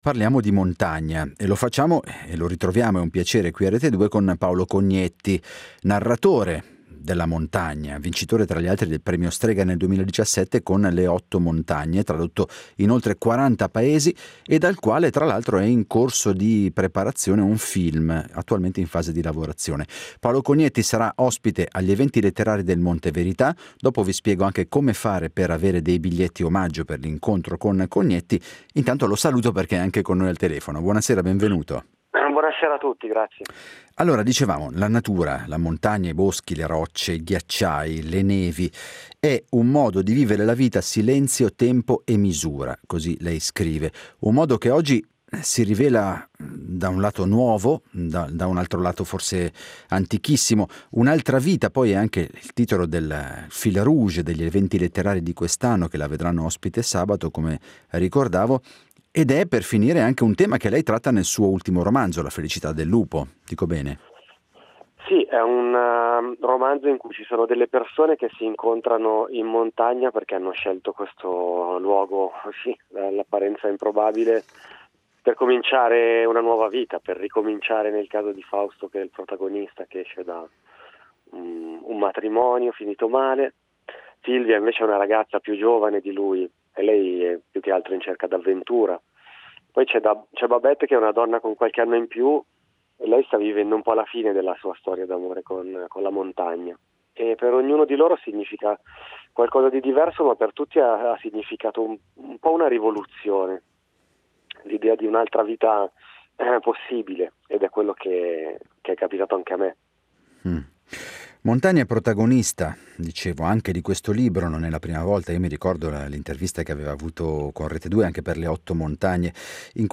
La Via Alta - Incontro con Paolo Cognetti
Come ogni venerdì, a Diderot, parliamo di montagna. Lo facciamo con Paolo Cognetti, uno degli autori letterari più legati a questa dimensione naturale.